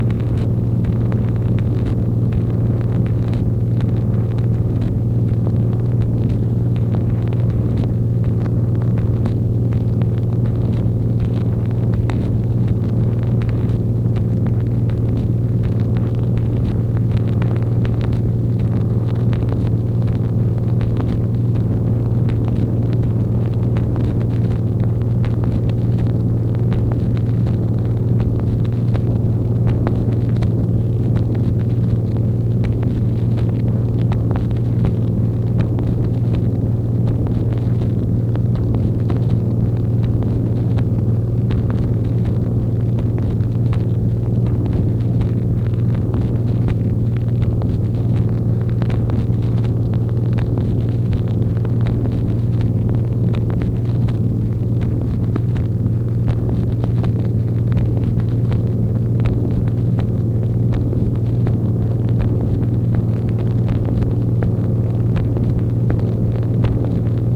MACHINE NOISE, June 12, 1964
Secret White House Tapes | Lyndon B. Johnson Presidency